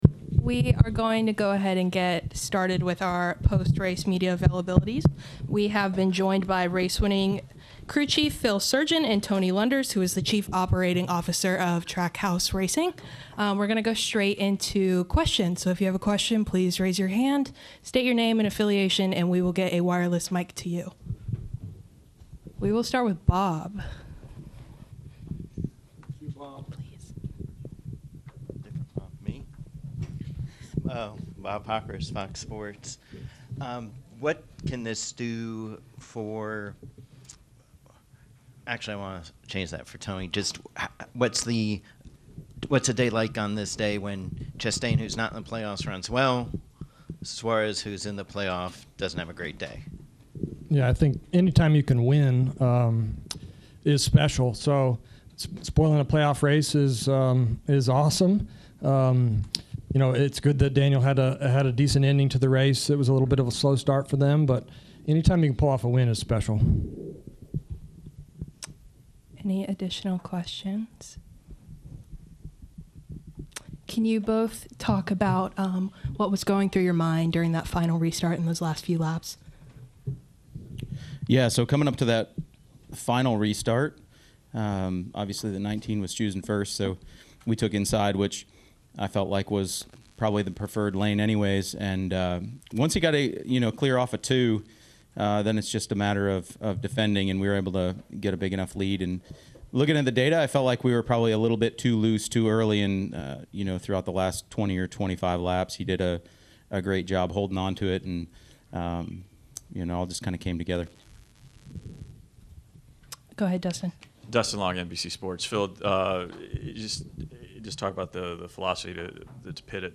Interviews:
NCS Race Winner – Ross Chastain (No. 1 Trackhouse Racing Chevrolet) –